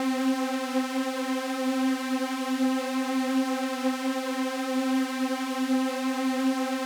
Trance Pad LogicPro.wav